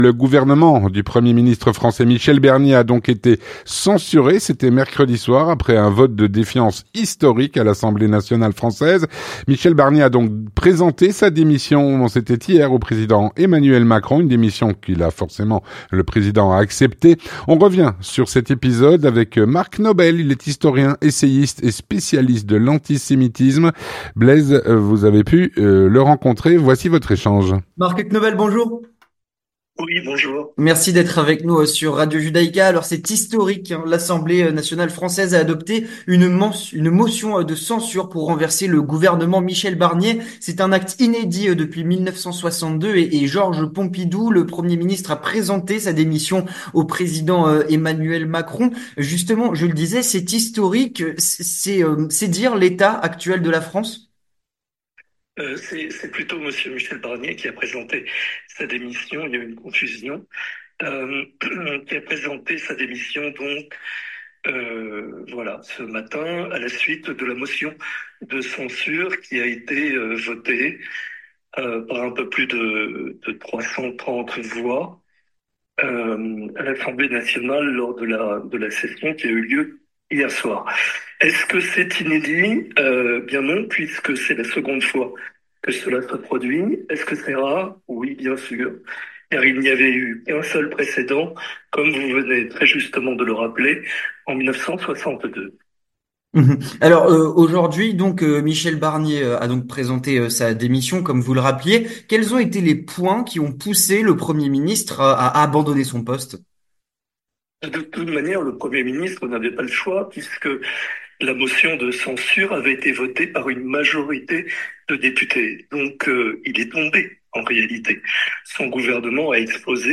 L'entretien du 18H - Le gouvernement du Premier Ministre français, Michel Barnier, a été censuré.